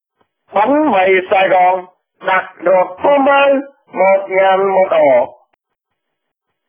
Tiếng Rao Bánh Mì MP3